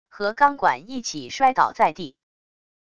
和钢管一起摔倒在地wav音频